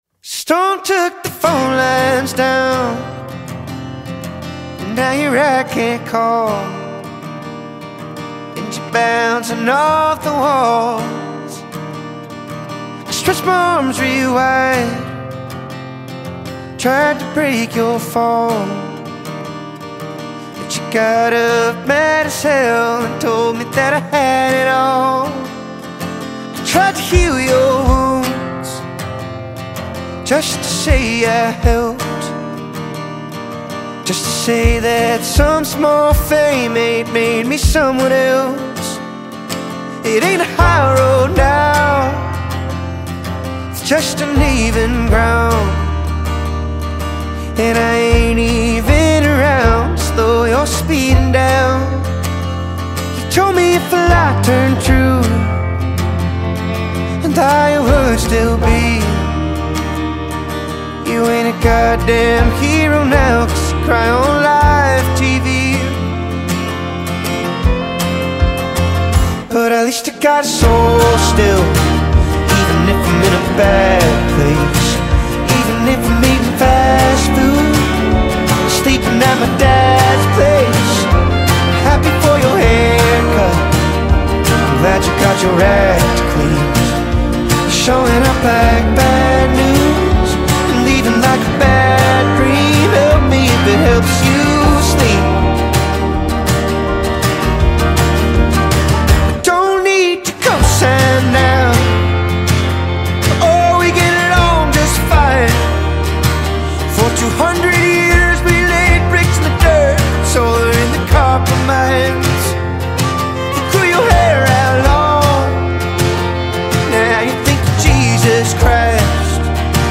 blending rhythm, soul, storytelling, and modern sound